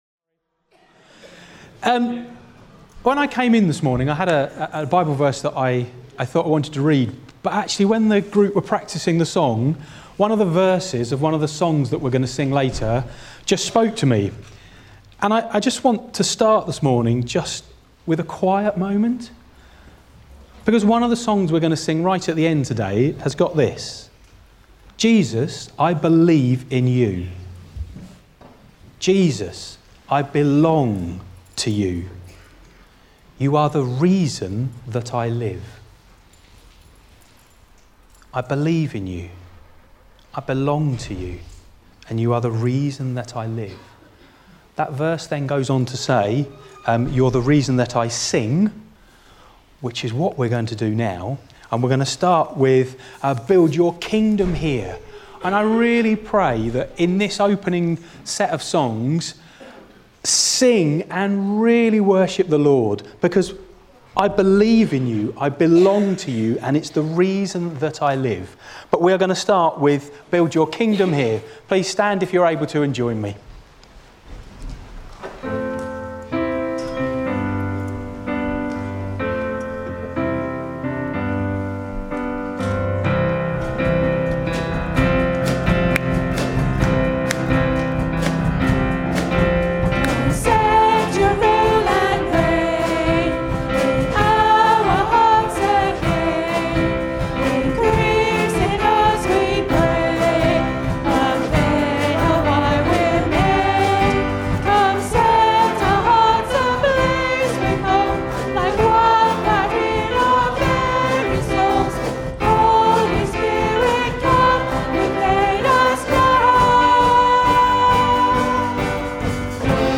25 January 2026 – Morning Service
Service Type: Morning Service